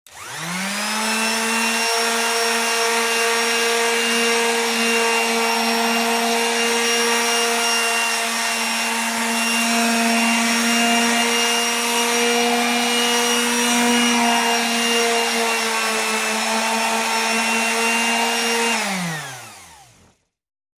Orbital sander operating at variable speeds. Tools, Hand Sander, Tool Motor, Sander